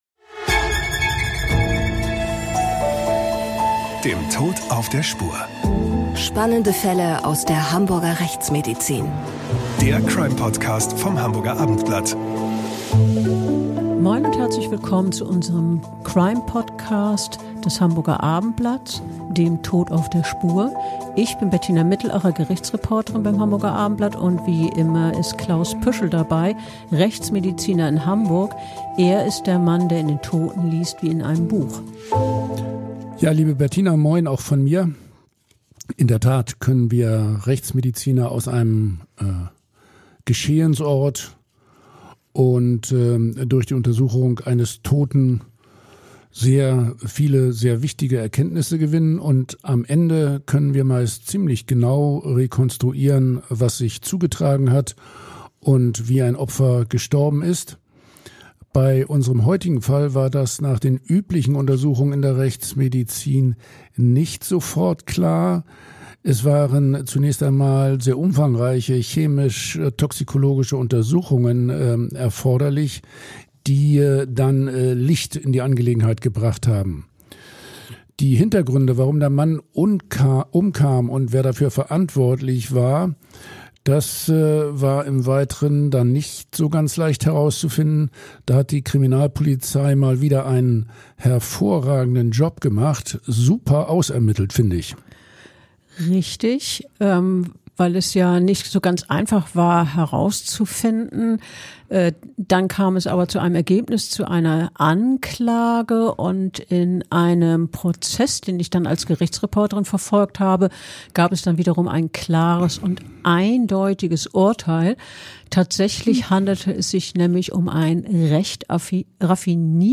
live auf der Caggtus 2024